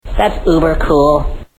Category: Television   Right: Personal
Tags: South park clips South park Stan Stan sounds Stan from South park